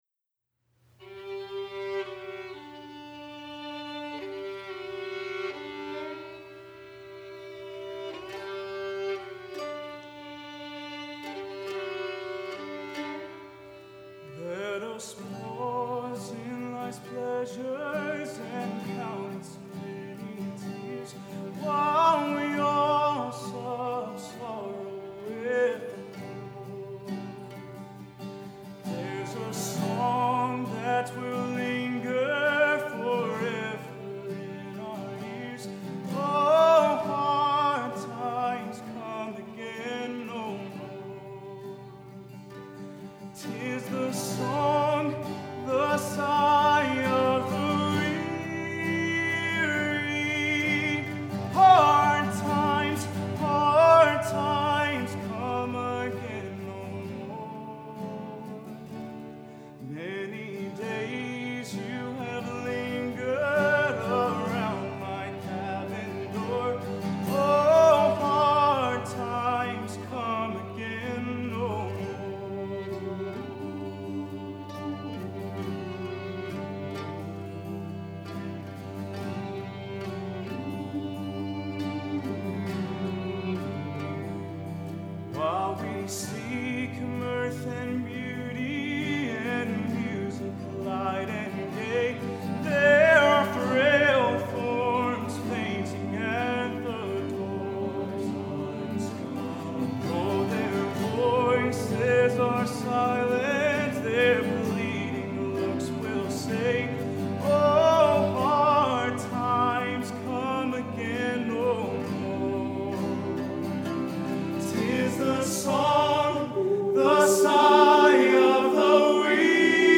Accompaniment:      Reduction, Fiddle, Cello, Guitar
Music Category:      Choral